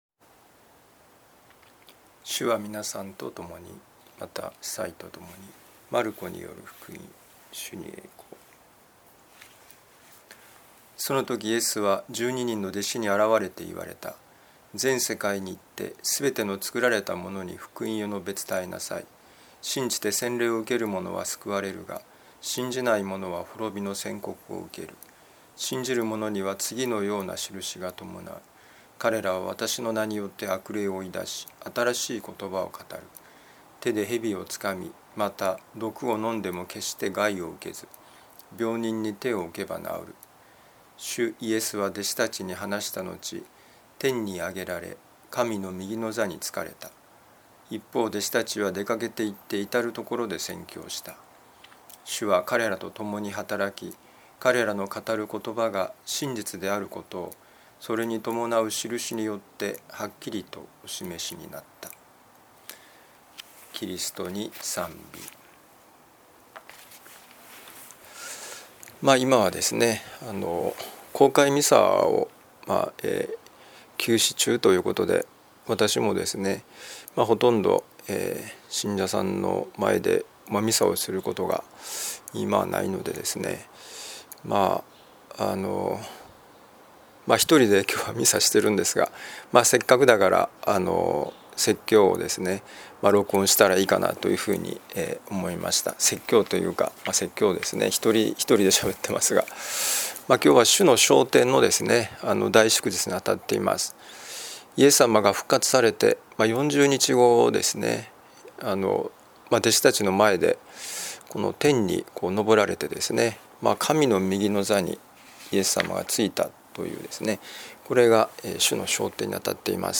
今日の福音書朗読とお説教の聞きどころ